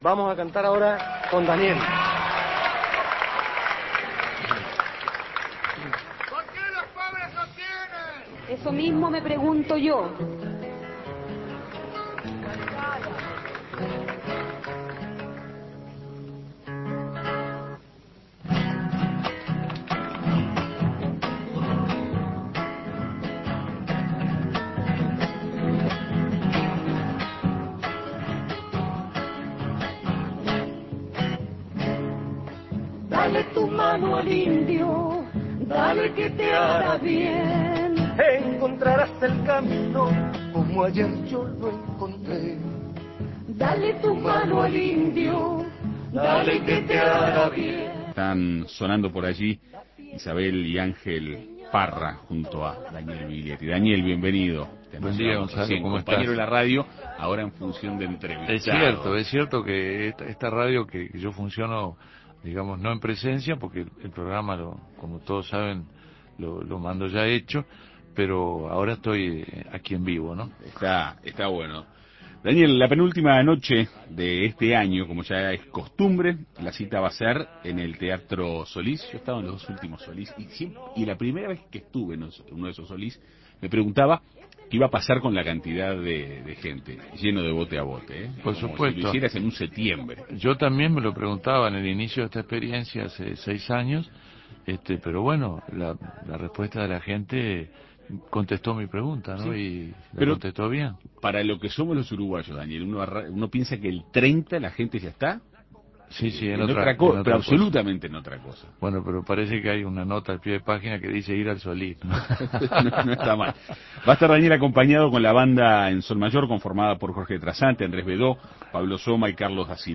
En Perspectiva Segunda Mañana recibió al artista para dialogar sobre este año que se va y el hombre nuevo al cual se ha encargado de cantarle durante toda su carrera.